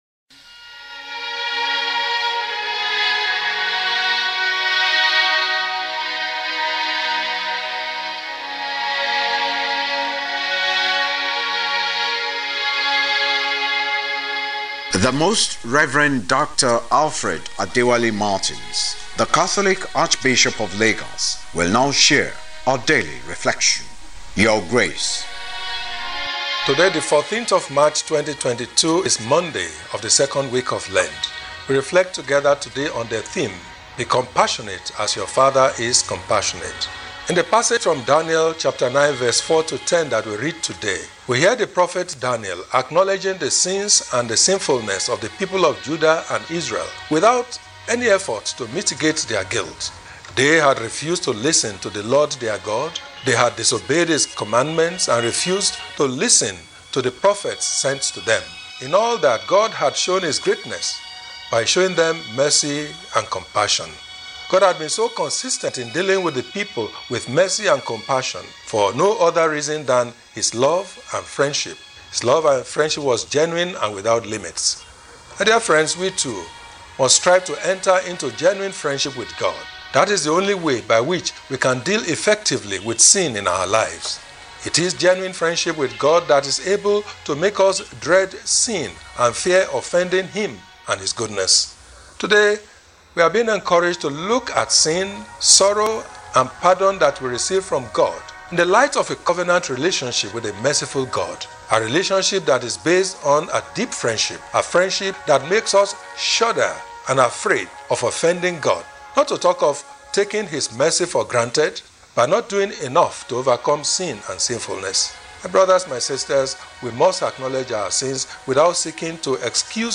Lenten-Talk-Monday-14.mp3